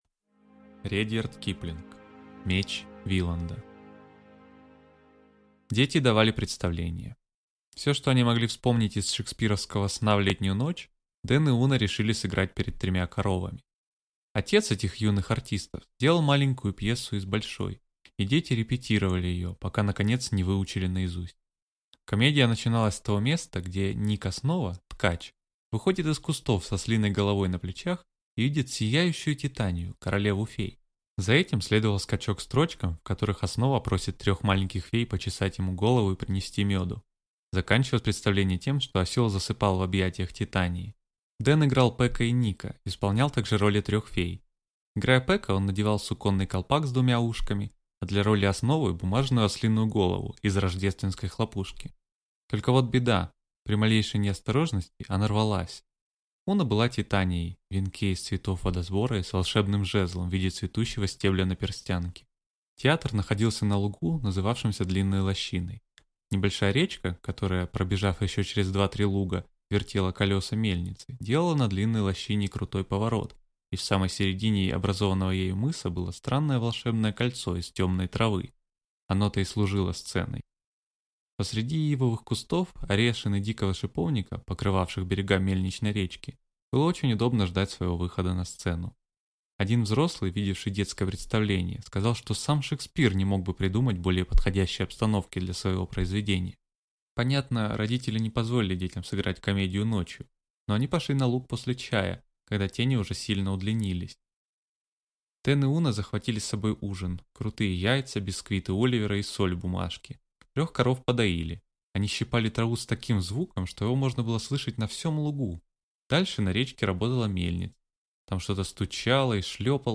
Аудиокнига Меч Виланда | Библиотека аудиокниг